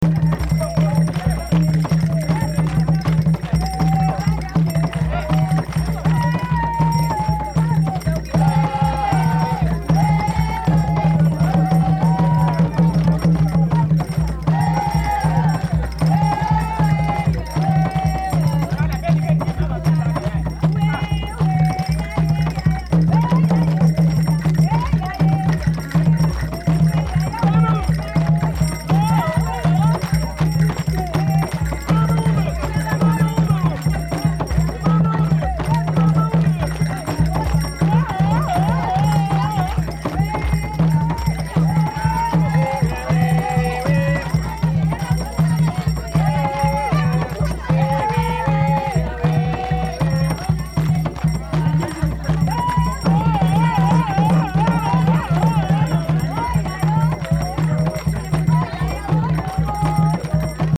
Grelot et sonnaille
Lorsqu’on secoue l’instrument, la bille qui frappe le récipient fait tinter celui-ci.
Ce type d’instrument apparaît dans d’enregistrements de nos archives sonores, réalisés parmi les peuples congolais suivants ; il est connu sous les noms vernaculaires suivants: